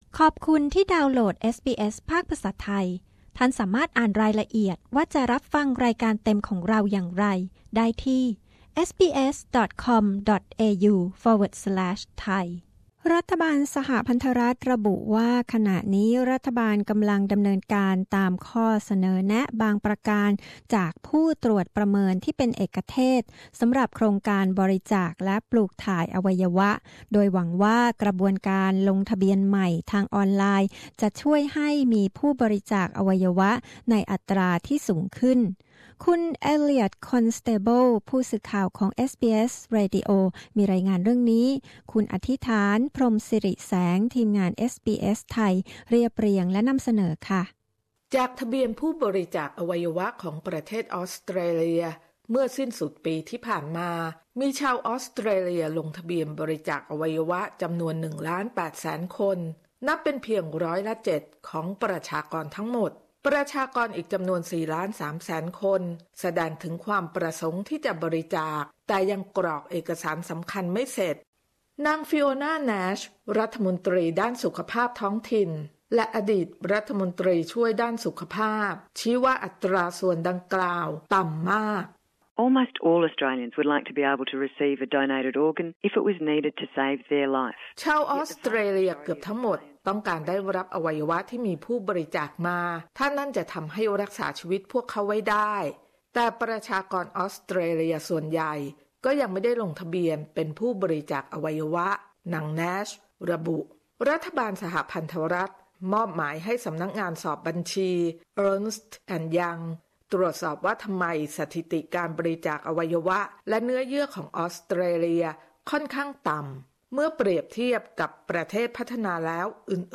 รัฐบาลออสเตรเลียเร่งรณรงค์ให้ประชาชนในออสเตรเลียลงทะเบียนบริจาคอวัยวะกันมากขึ้น เพราะมีความต้องการมาก แต่มีผู้ลงทะเบียนน้อยนิด เอสบีเอส ไทย ยังได้คุยกับคนไทยในเมลเบิร์น ว่าคิดอย่างไรในการบริจาคอวัยวะ และหากต้องการลงทะเบียนบริจาคอวัยวะในเมลเบิร์นจะทำอย่างไร